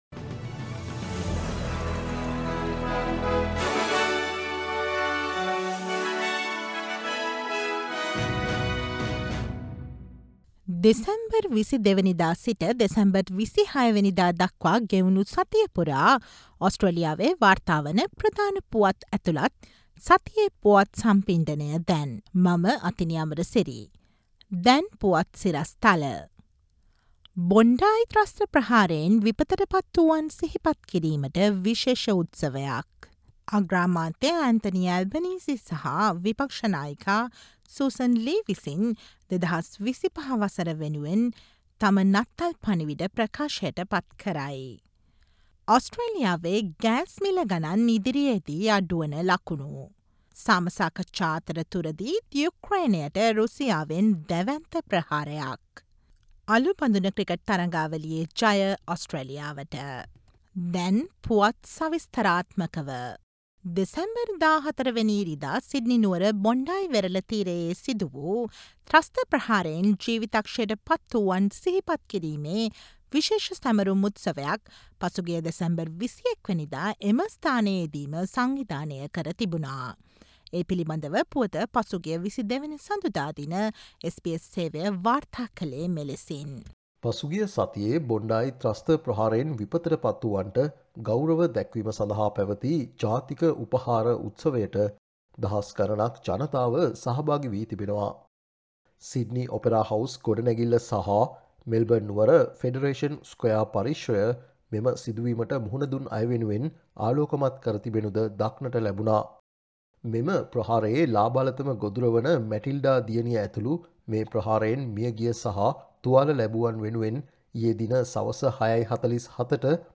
දෙසැම්බර් 22වන දා සිට දෙසැම්බර් 26වන දා දක්වා වන මේ සතියේ ඕස්ට්‍රේලියාවෙන් වාර්තා වන පුවත් ඇතුළත් SBS සිංහල සේවයේ සතියේ පුවත් ප්‍රකාශයට සවන් දෙන්න